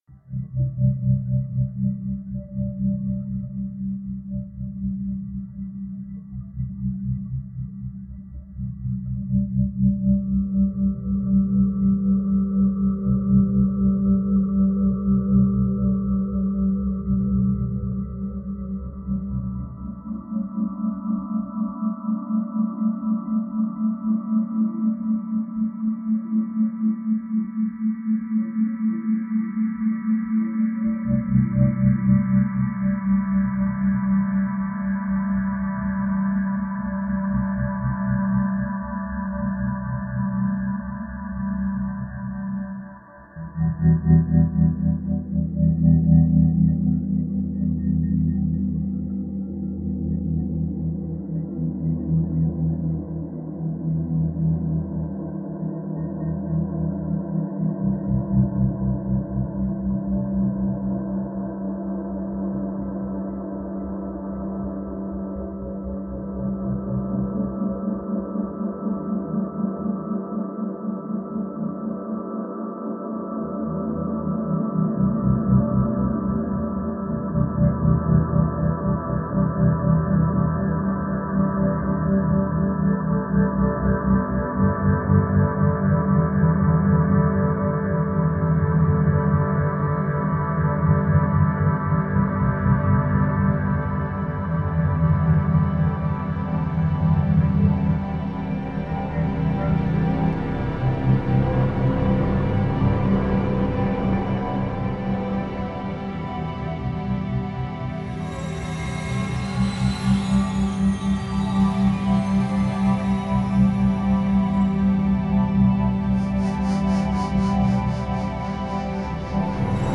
A trance piece by our free improvisation duo.
electric guitar, various effect processing
Max/MSP laptop Floating Under Ice Back